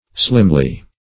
Slimly \Slim"ly\, adv.